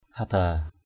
/ha-d̪a:/ (d.) chông = piquet.